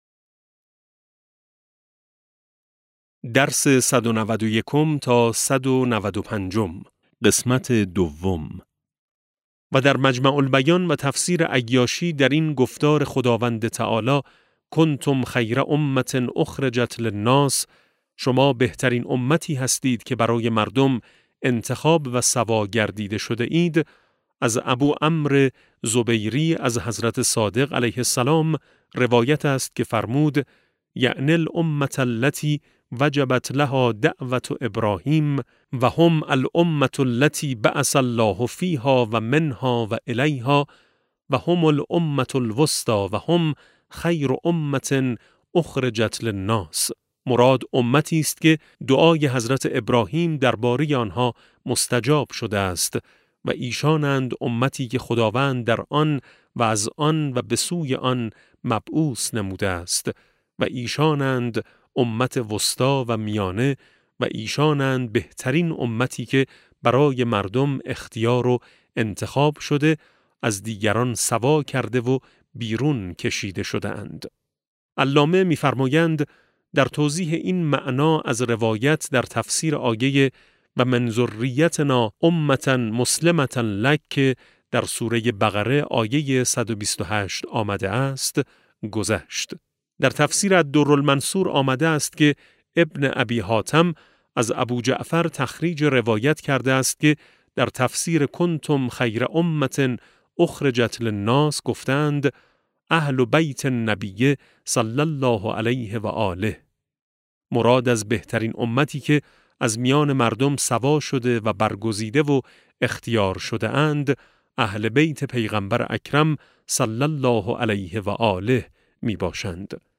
کتاب صوتی امام شناسی ج 13 - جلسه14